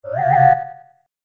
Звуки из игры Супер Марио 64 — SFX
Деформация